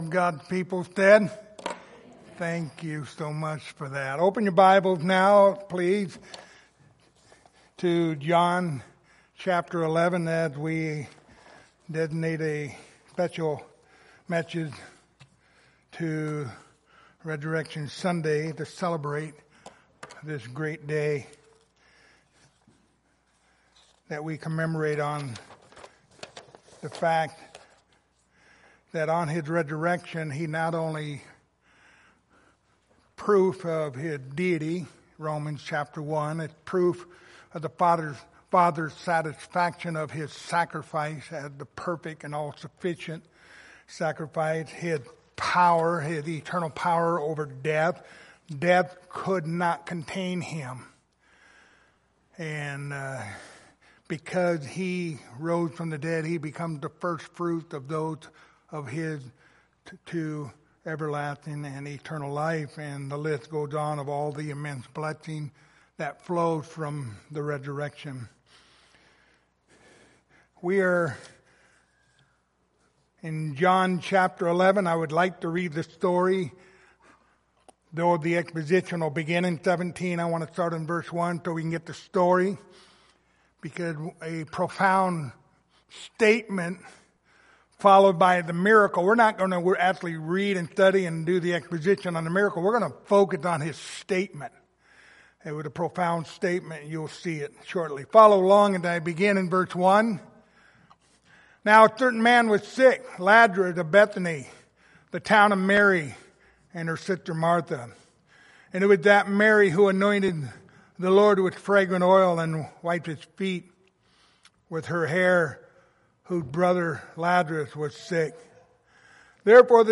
John 11:17-27 Service Type: Sunday Morning Topics